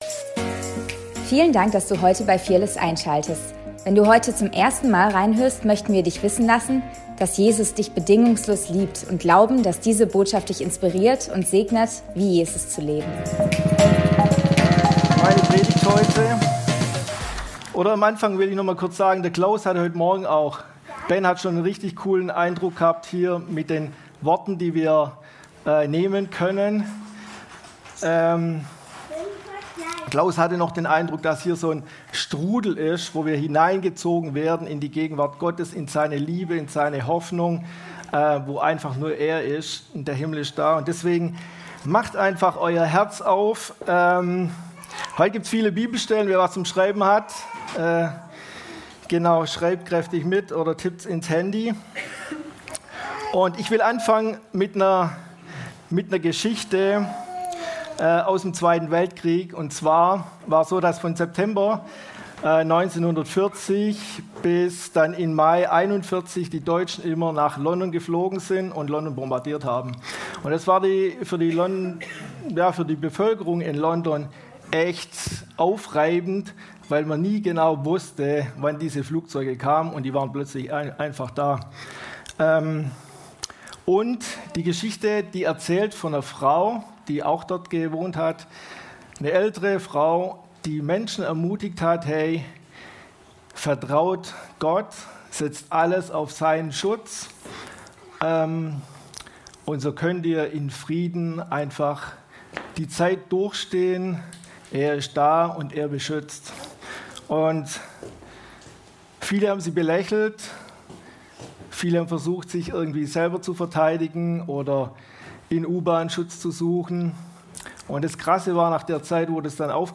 Predigt vom 02.03.2025